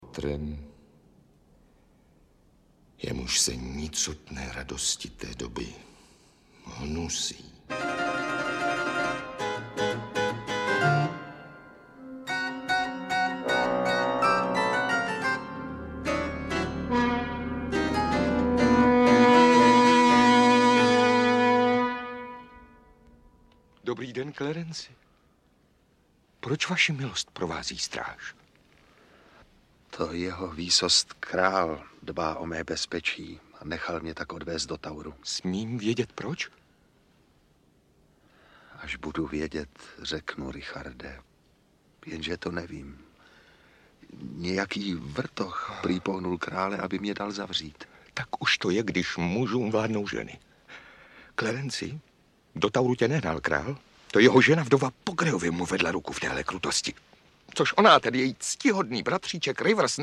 Audiobook
Read: Leopolda Dostalová